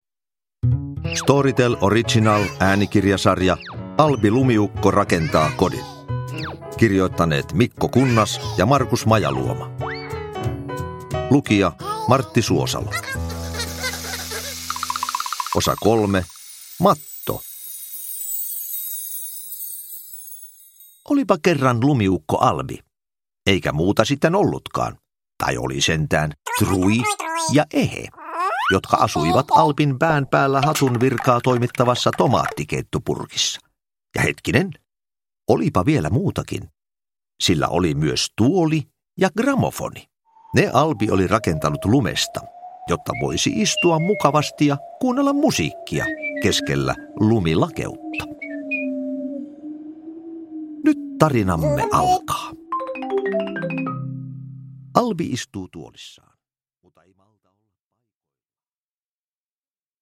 Albi rakentaa kodin: Matto – Ljudbok – Laddas ner
Uppläsare: Martti Suosalo